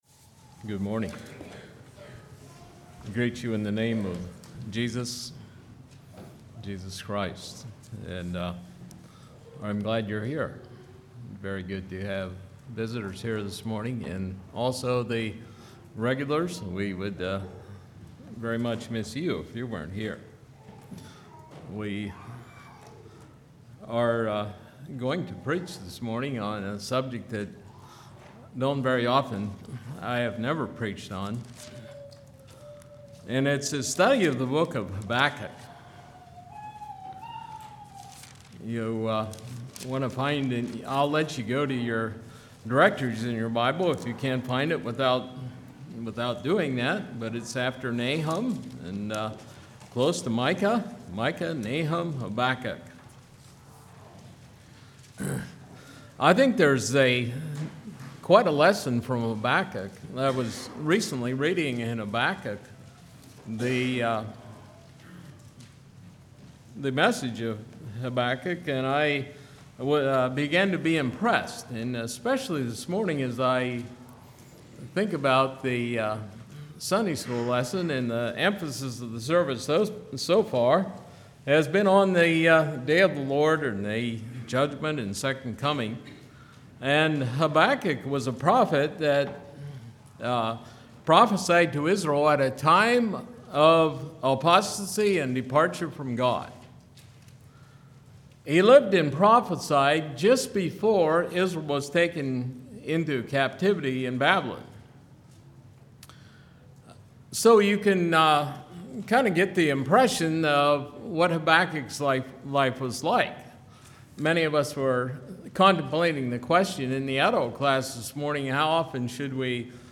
Play Now Download to Device Habakkuk Congregation: Mount Joy Speaker